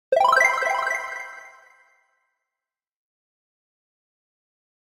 SE（魔法）